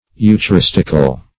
Eucharistic \Eu`cha*ris"tic\, Eucharistical \Eu`cha*ris"tic*al\,